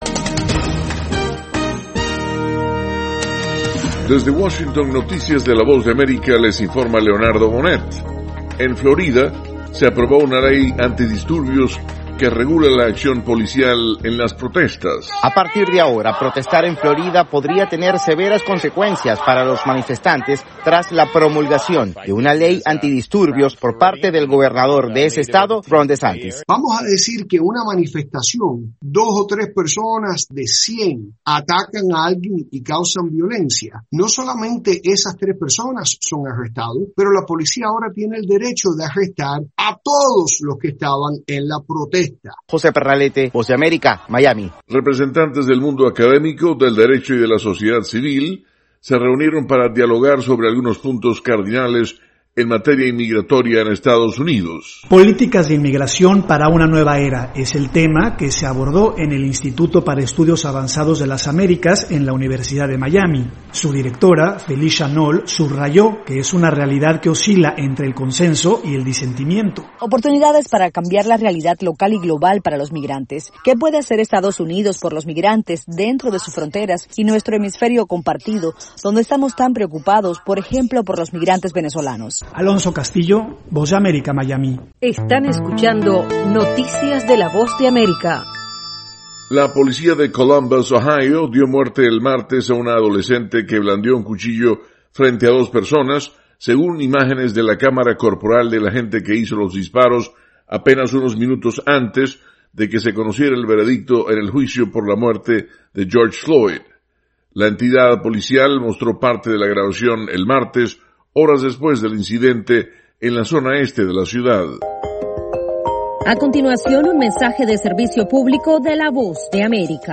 Avance Informativo - 10:00 AM